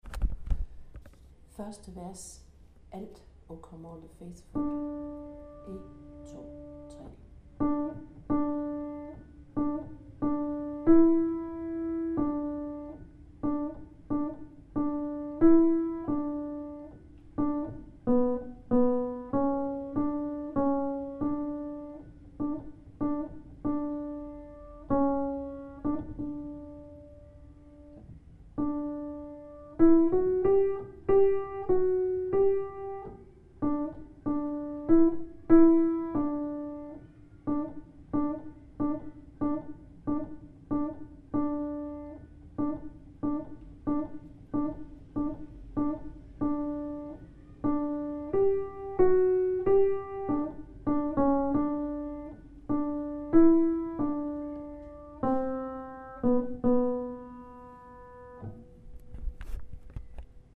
Alt